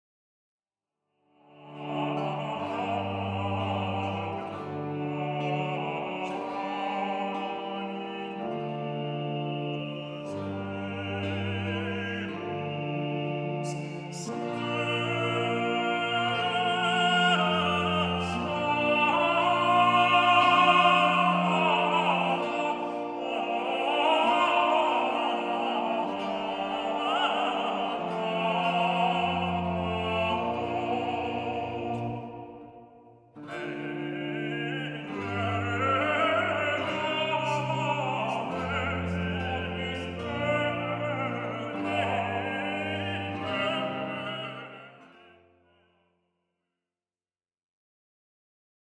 Un manifeste flamboyant de la révolution baroque